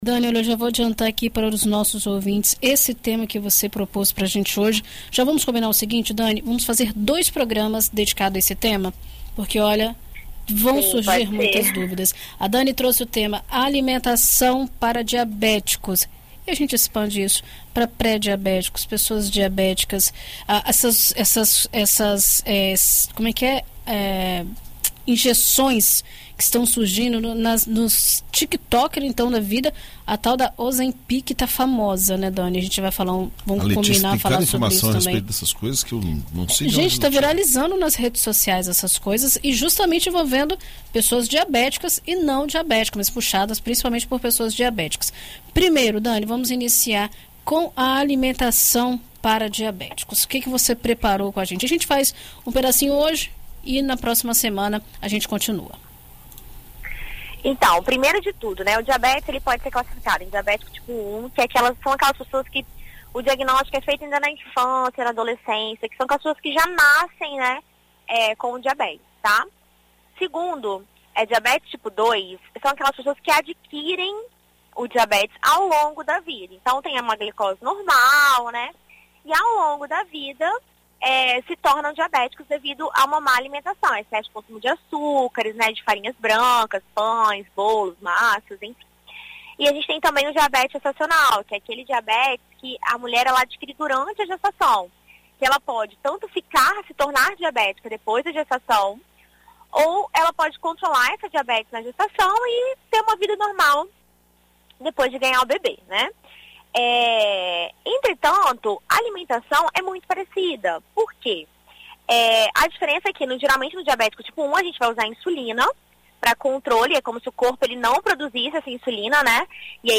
Na coluna Viver Bem desta quarta-feira (28), na BandNews FM Espírito Santo